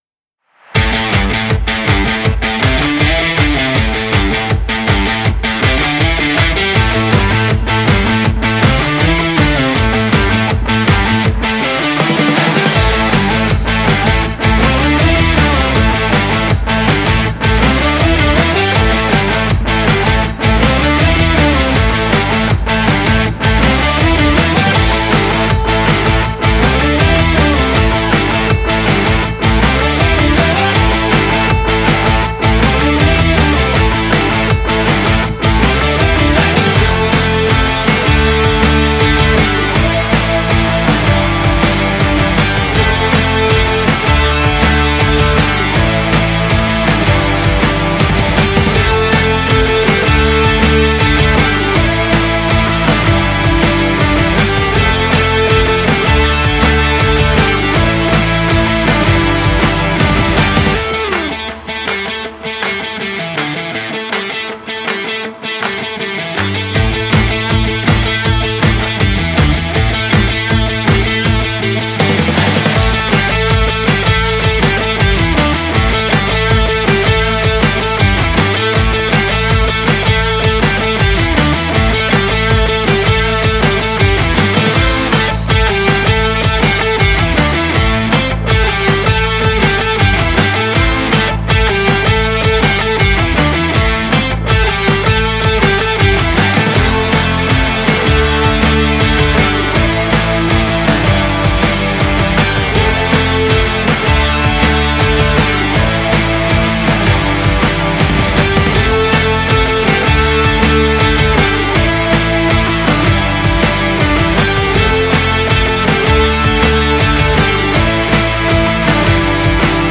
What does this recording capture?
Genre: indierock.